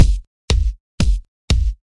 描述：这是Sylenth 1和Amp FX'S一起使用的
标签： 混响 击鼓声 样品 EDM 舞曲 小鼓 Psytrance 压缩 捶击 电子乐 俱乐部 形实转换 采样 单冲 精神恍惚
声道立体声